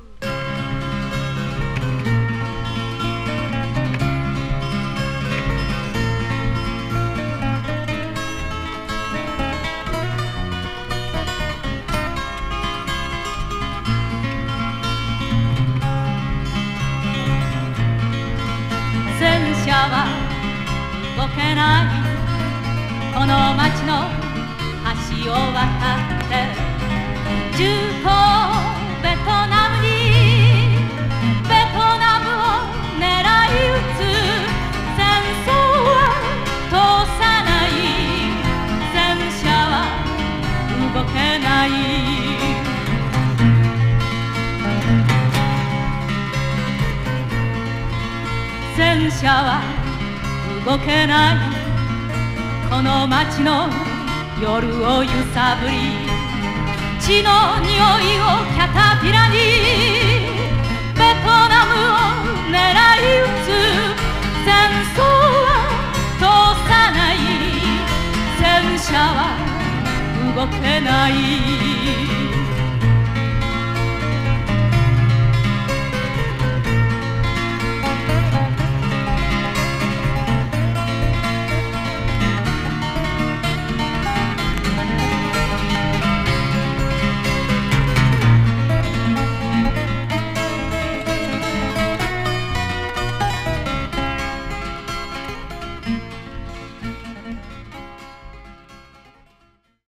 女性SSW